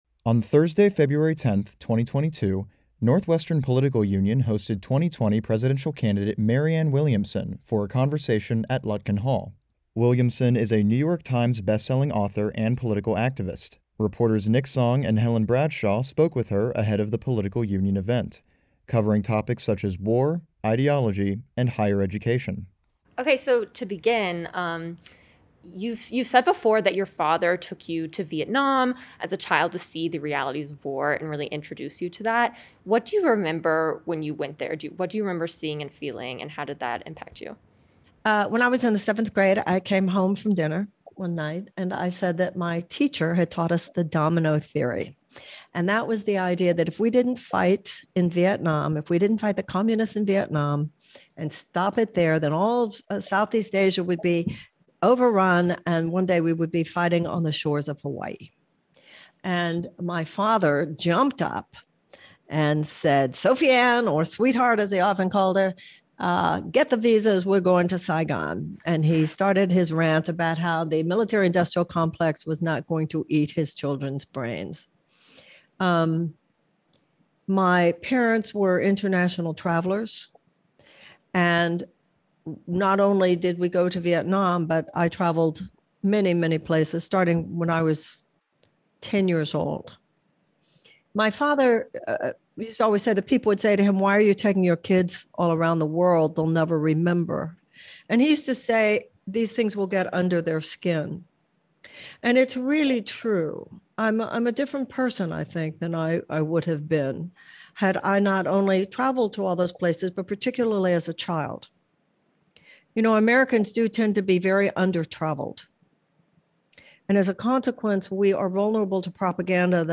On Thursday, February 10th, 2022, Northwestern Political Union hosted 2020 presidential candidate Marianne Williamson for a conversation at Lutkin Hall.